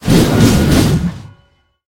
b_skill_bladeRotate.mp3